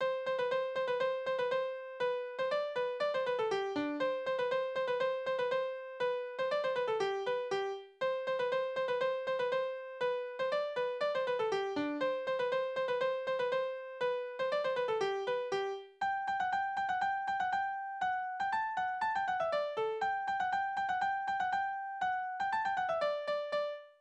« 10887 » Heuras, Heuras Tanzverse: Tonart: G-Dur Taktart: 2/4 Tonumfang: Duodezime Anmerkung: - kein Text; höchstwahrscheinlich instrumental Externe Links: Sprache: hochdeutsch eingesendet von Aug.